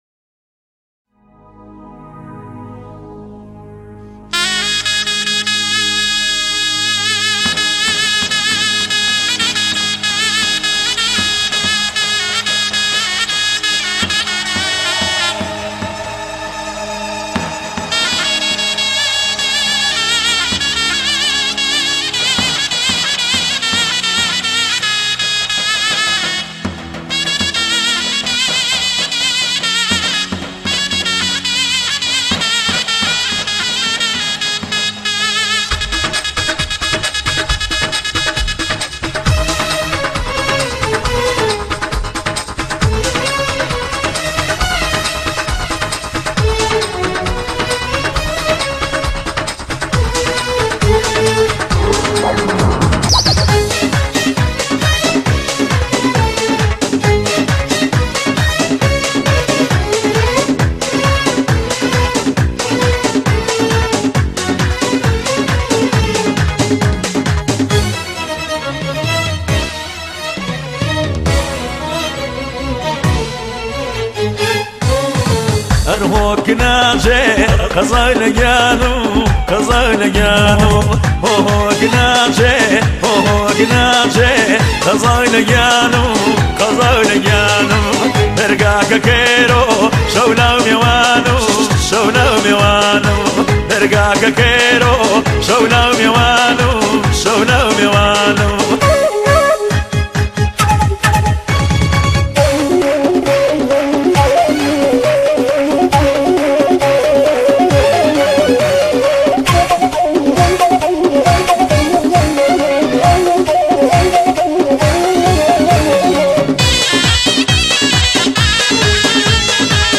یک قطعه موسیقی شاد کردی